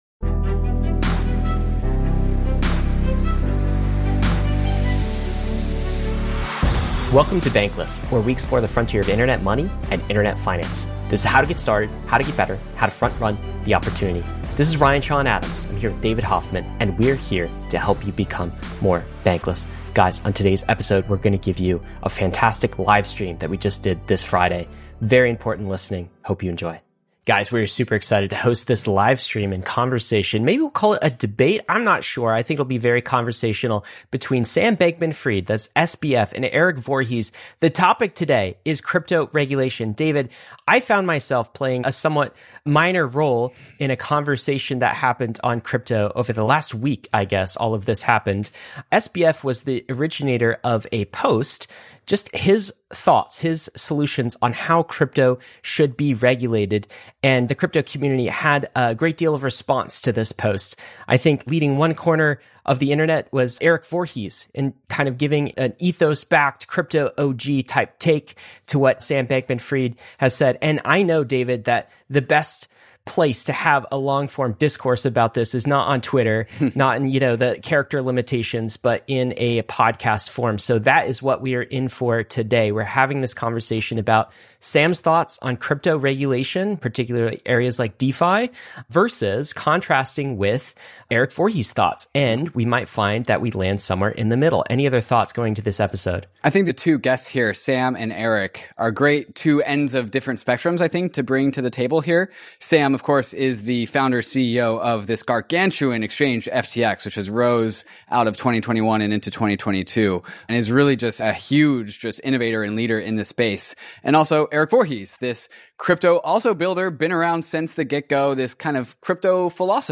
A debate between two crypto titans on how to regulate crypto. Whether to regulate crypto?